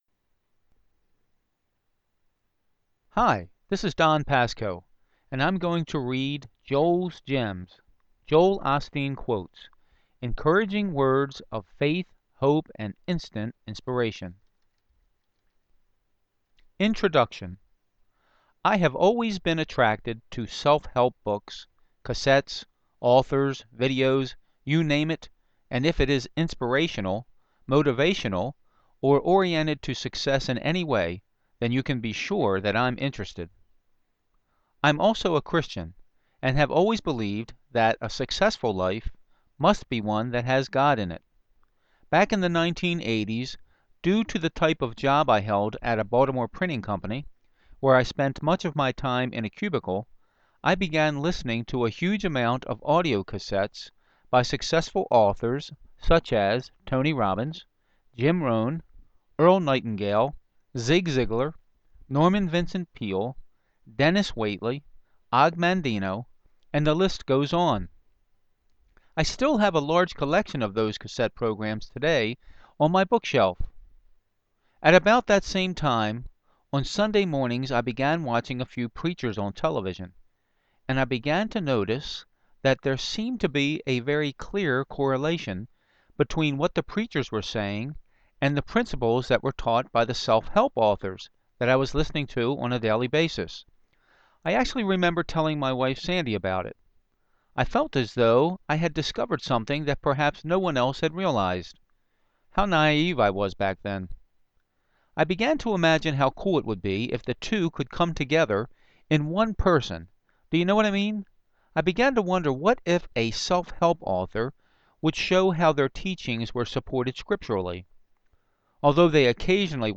Joels+Gems+Audiobook.mp3